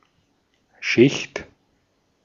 Ääntäminen
Vaihtoehtoiset kirjoitusmuodot (vanhentunut) dutie (vanhahtava) dooty Synonyymit obligation tax commitment Ääntäminen US : IPA : [ˈdju.ti] UK : IPA : /ˈdjuː.ti/ US : IPA : /duːɾi/ Tuntematon aksentti: IPA : /ˈdu.ti/